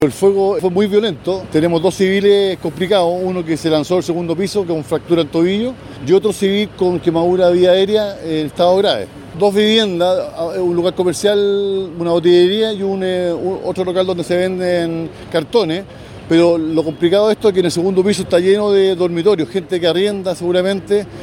cu-bomberos-por-incendio-matta.mp3